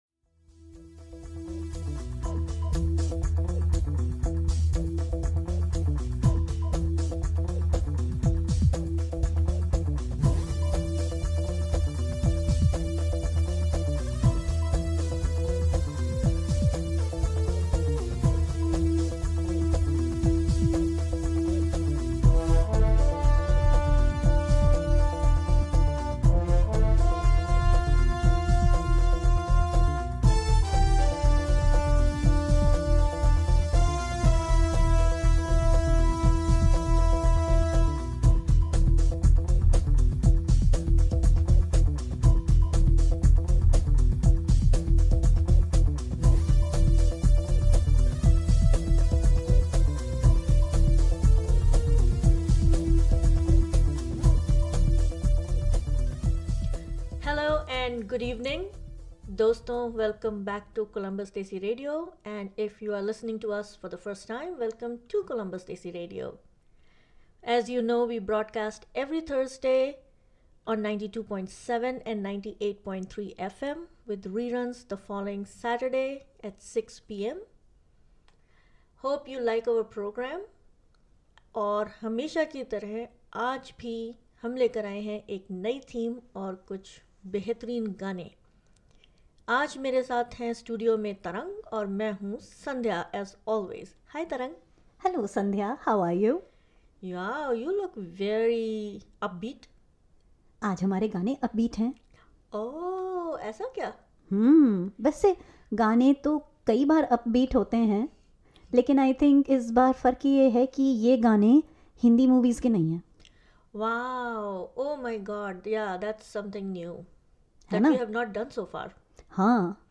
Disco..pop...desi MTV...remember the days...